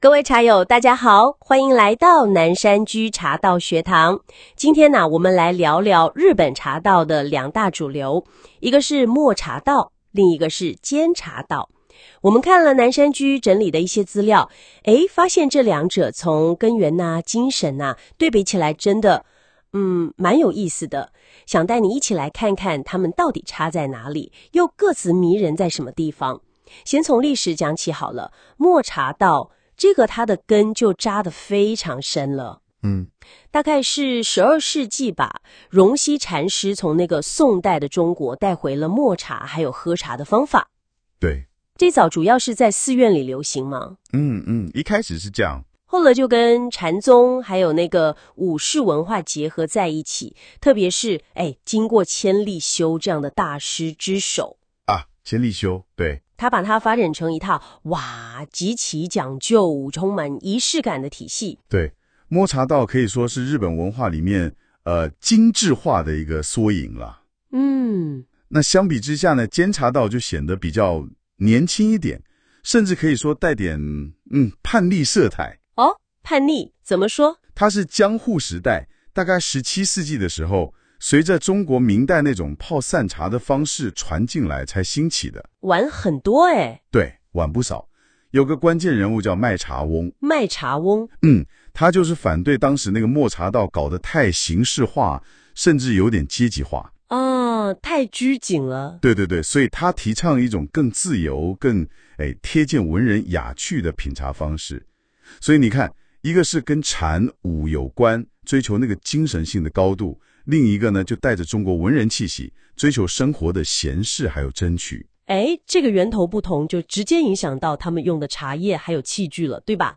【語音導讀】抹茶道與煎茶道比較（6分07秒）